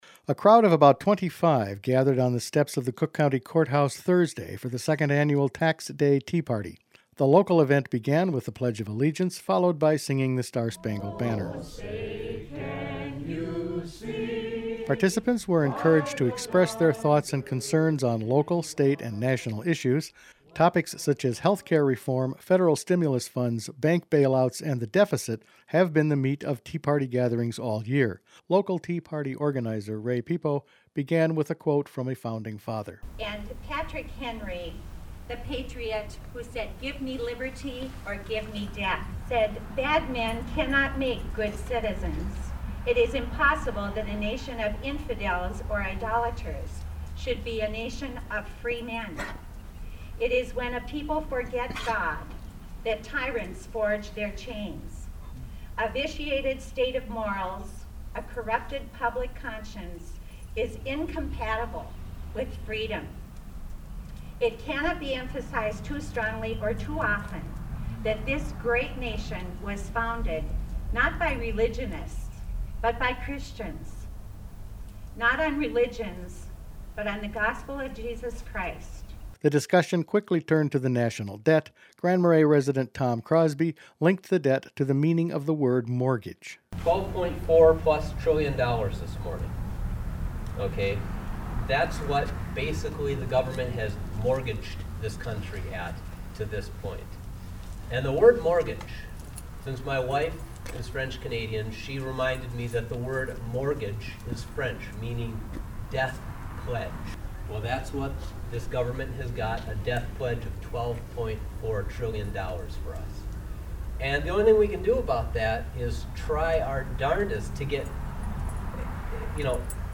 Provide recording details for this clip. County Tea Party gathering held on courthouse steps